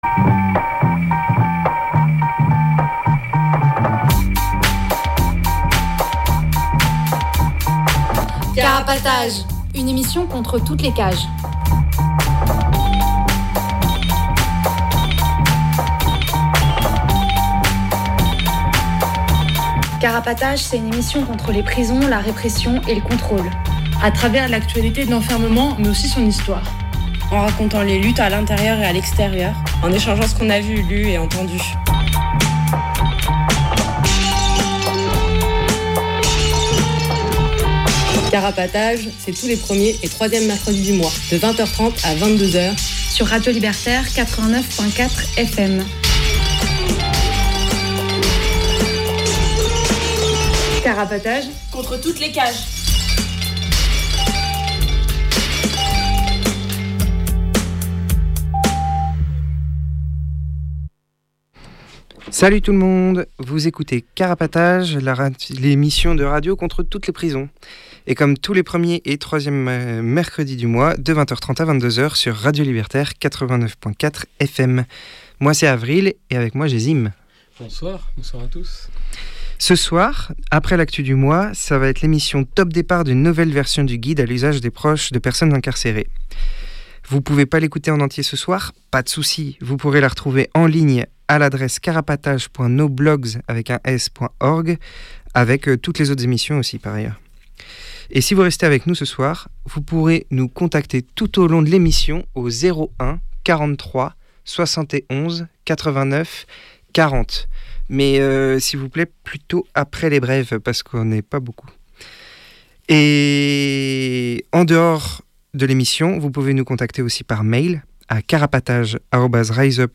La dernière version du guide à l’usage des proches de personnes incarcérées datait de fin 2013 mais, 12 ans plus tard, une nouvelle édition vient de voir le jour ! Cette émission, enregistré depuis la salle d’impression du bouquin par les gens qui l’ont produit, édité et imprimé, est donc celle de lancement de cette nouvelle édition !